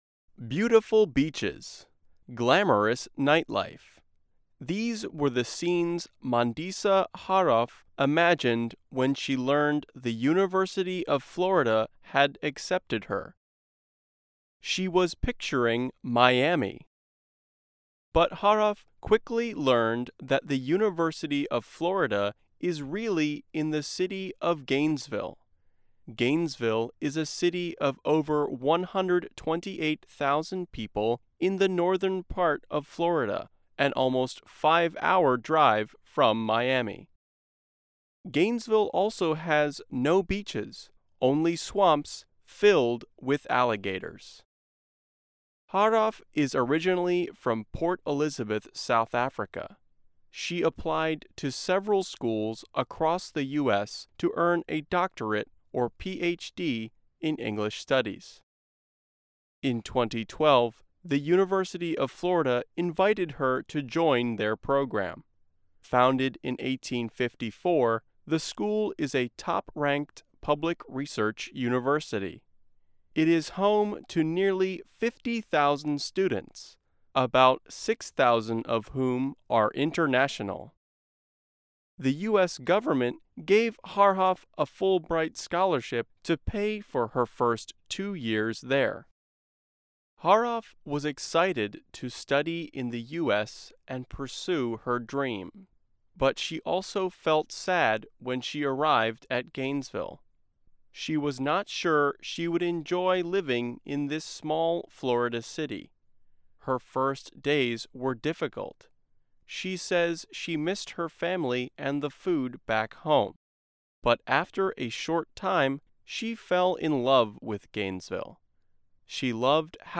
Learn English as you read and listen to a weekly show about education, including study in the U.S. Our stories are written at the intermediate and upper-beginner level and are read one-third slower than regular VOA English.